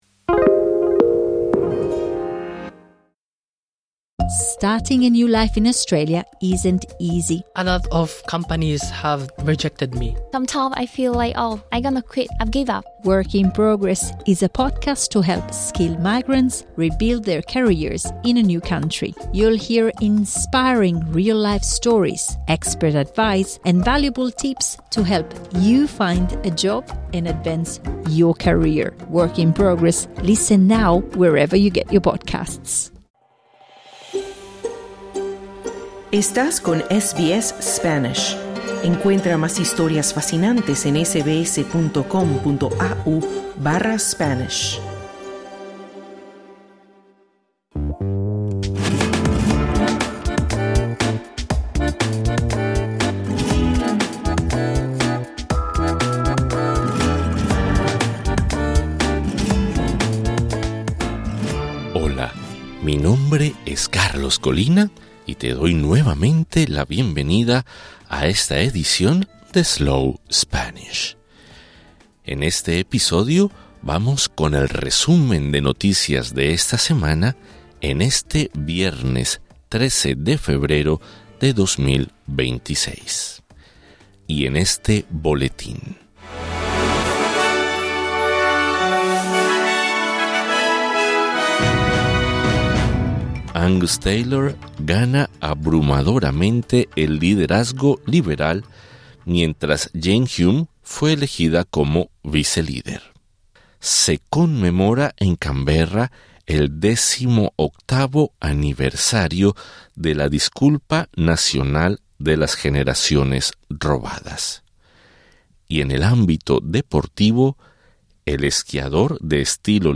Welcome to SBS Slow Spanish, a podcast designed in Australia specifically for those interested in learning the second most spoken language in the world. This is our weekly news flash in Spanish for 13 February 2026.